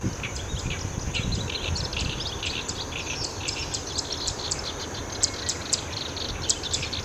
セッカ
なので今日は昨日、録音してありましたオオヨシキリ、コヨシキリ、セッカの合唱です。
オオヨシキリのさえずりのバックに騒がしく鳴いているのがコヨシキリです。